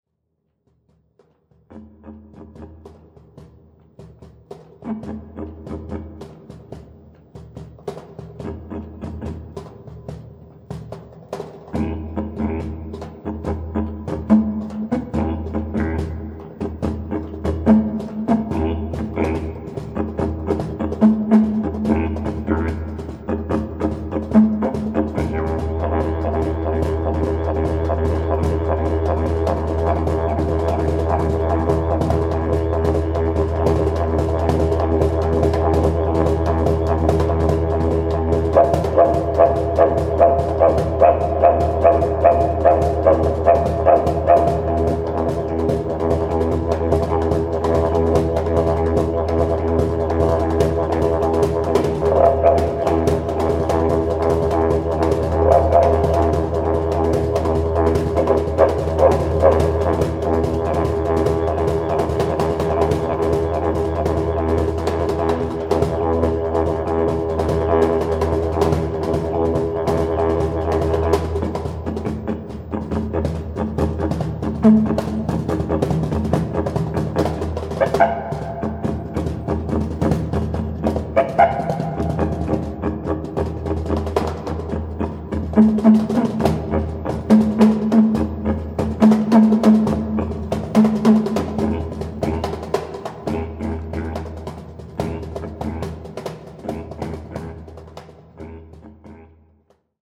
Gesang, Didgeridoo, Duduk, Hang
Keyboard
Live Klang-Eindrücke von ihrem Konzert